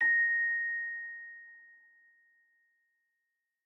celesta1_10.ogg